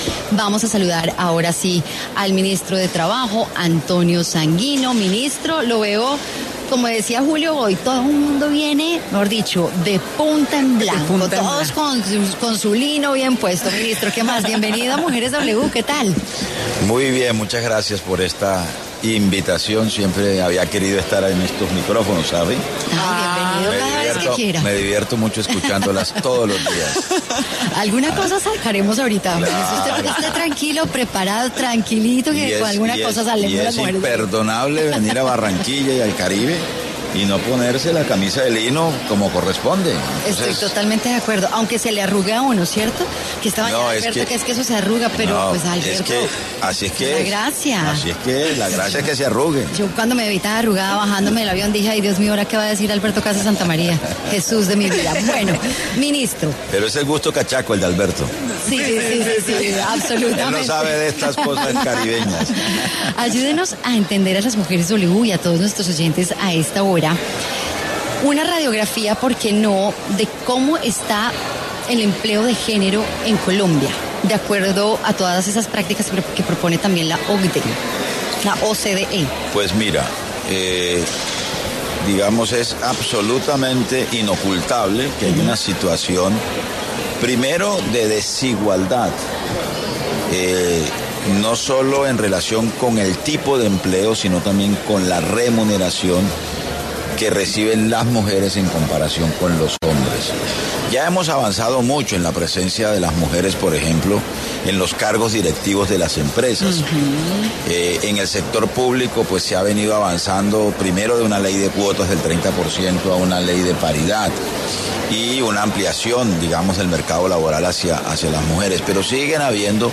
El jefe de la cartera laboral habló en Mujeres W sobre los esfuerzos que se están haciendo por la paridad de género y la seguridad laboral en el marco del Foro de Desarrollo Local de la OCDE.